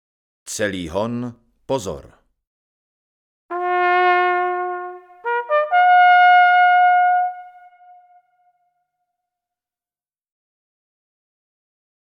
Troubení při lovech, honech a jiných mysliveckých akcích
K troubení se obvykle používá B-trubky bez ventilové techniky, tzv. borlice; k dostání je i zmenšený typ tzv. kapesní borlice.